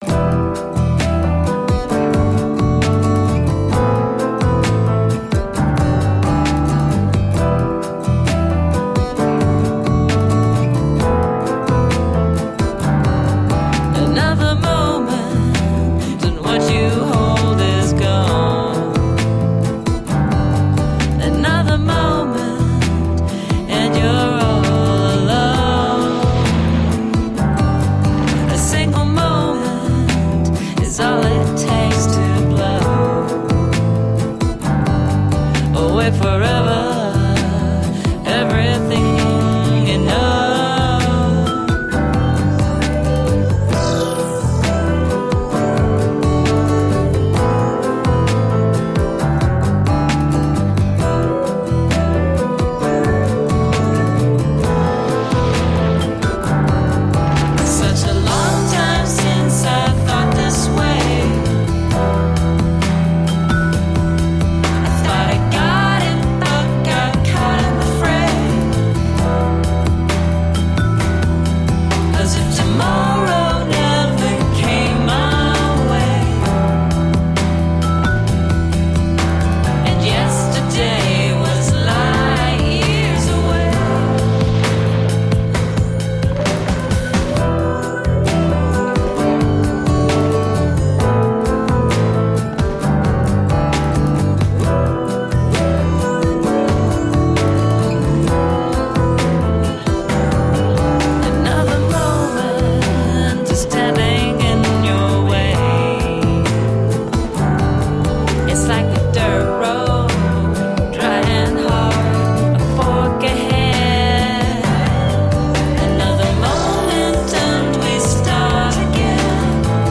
Tagged as: Alt Rock, Pop